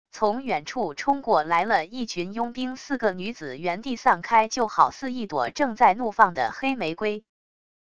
从远处冲过來了一群佣兵四个女子原地散开就好似一朵正在怒放的黑玫瑰wav音频生成系统WAV Audio Player